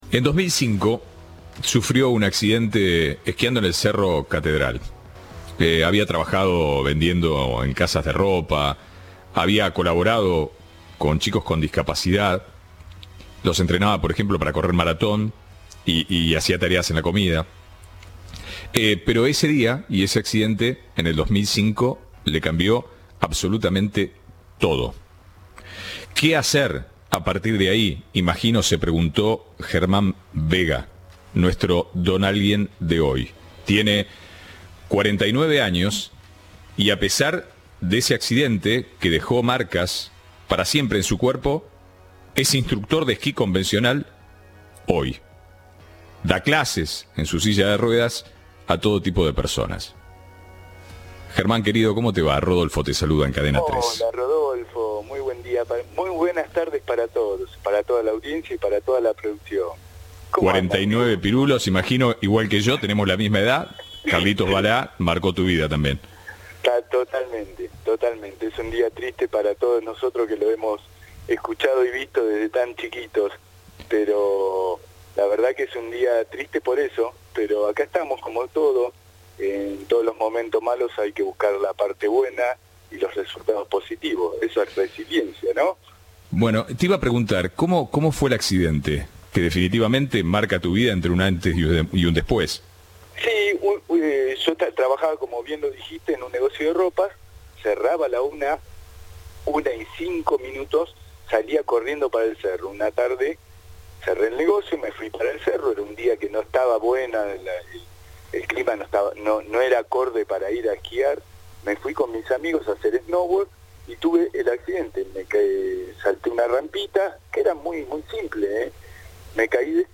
Entrevista de Rodolfo Barili